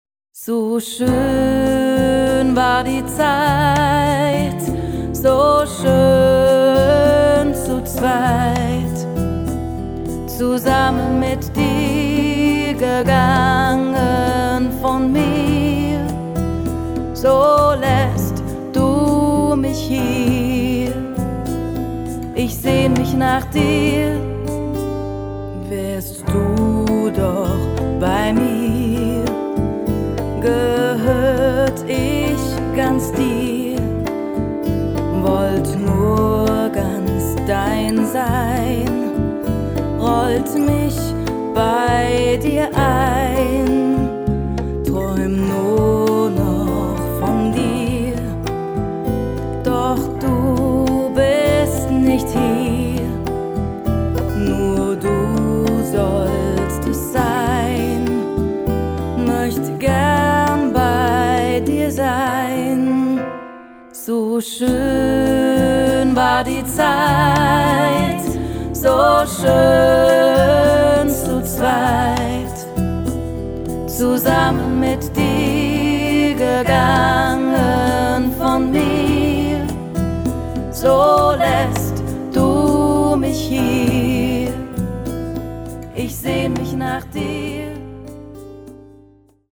Genre: Ballade
Stereo